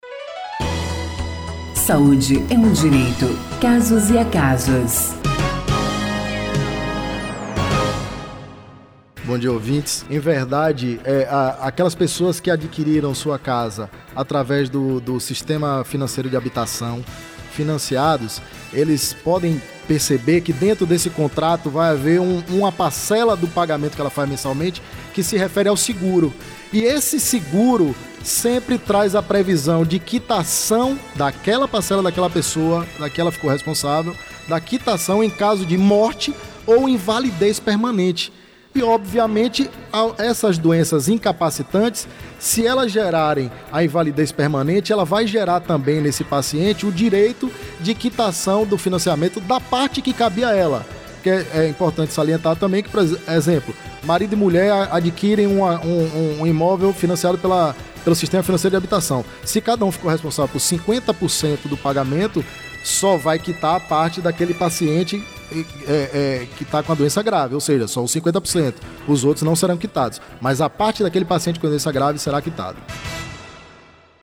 O assunto foi tema do quadro Saúde é um direito, que vai ao ar toda quarta-feira, no Programa Saúde no ar, veiculado pela rádio na Rede Excelsior de Comunicação: AM 840 AM, FM 106.01, Recôncavo AM 1460 e Rádio Saúde no ar.